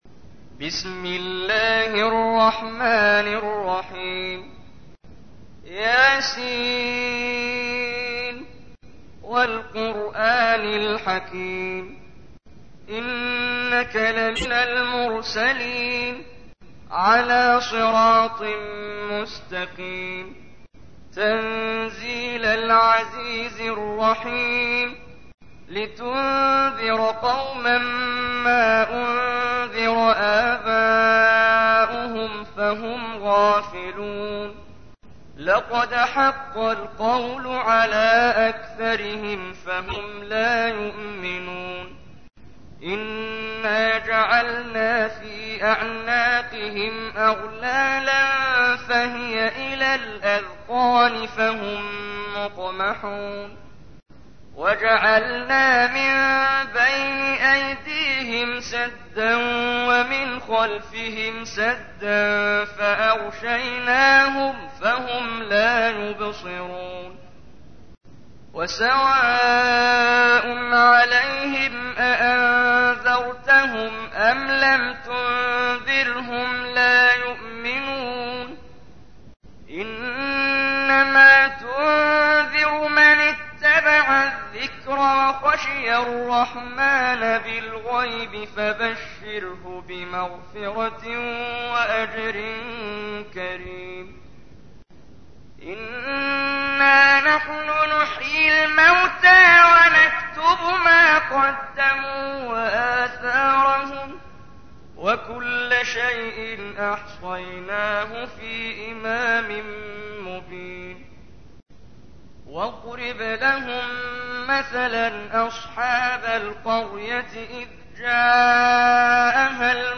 تحميل : 36. سورة يس / القارئ محمد جبريل / القرآن الكريم / موقع يا حسين